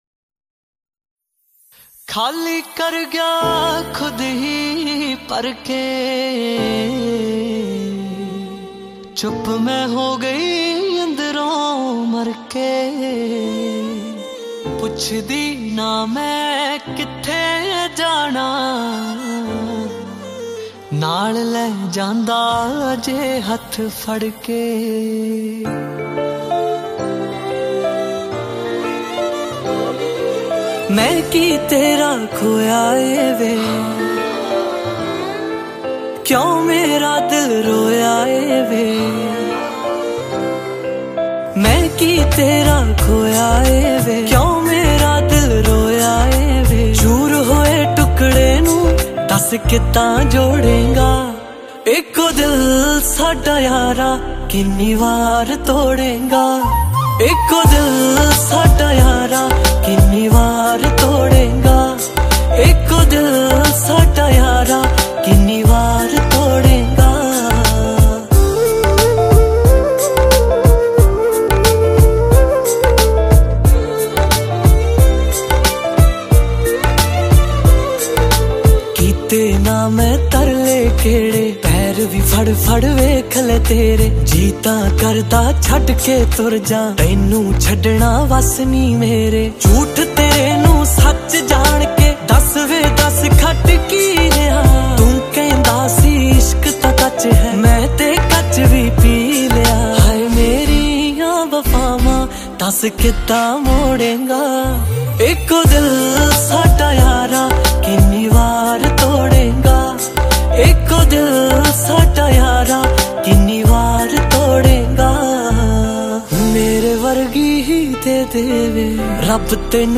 Punjabi Songs